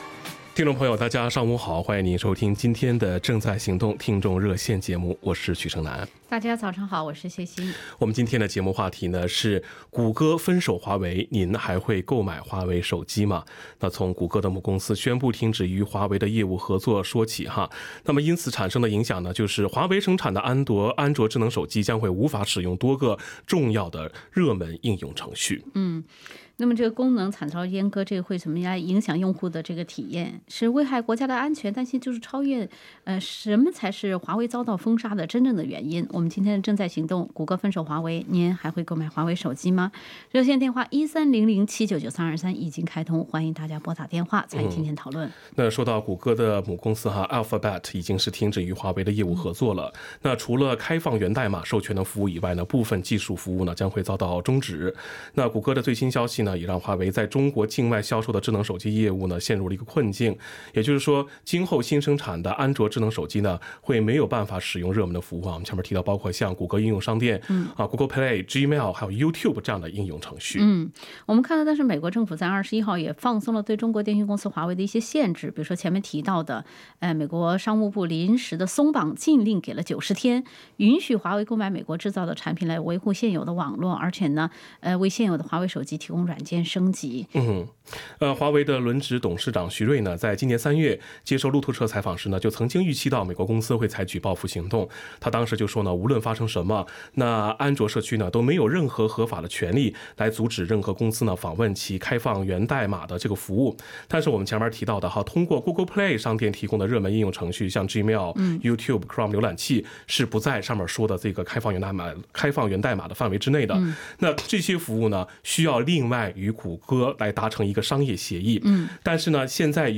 谷歌阉割华为，华为手机我该拿你怎么办？【正在行动】热线